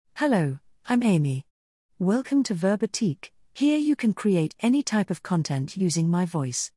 Amy — Female British English AI voice
Amy is a female AI voice for British English.
Voice: AmyGender: FemaleLanguage: British EnglishID: amy-en-gb
Voice sample
Listen to Amy's female British English voice.